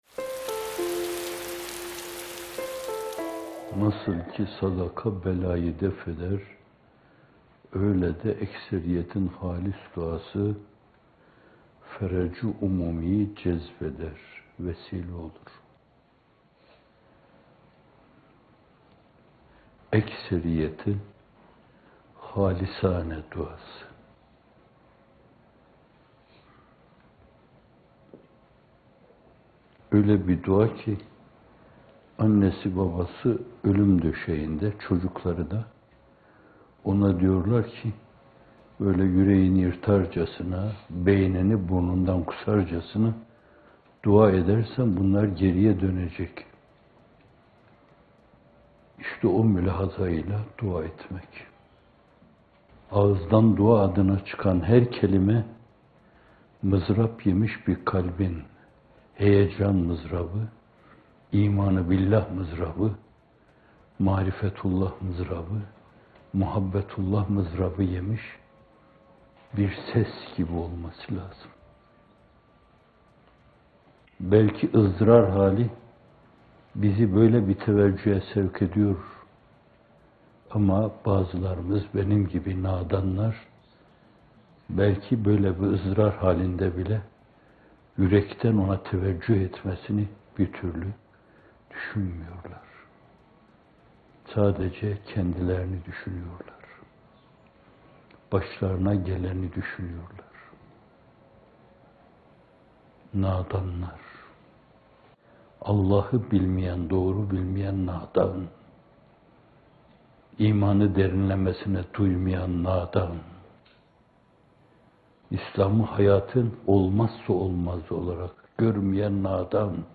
İkindi Yağmurları – Ölüp Ölüp Dirilmek - Fethullah Gülen Hocaefendi'nin Sohbetleri